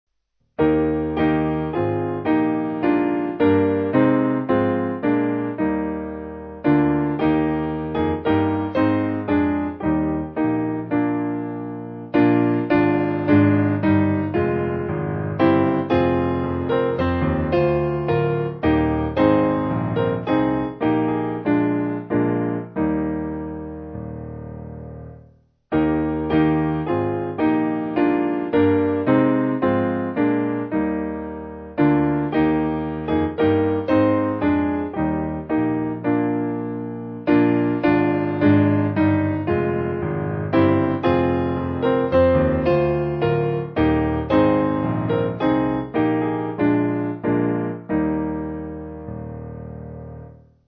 Simple Piano
(CM)   3/Eb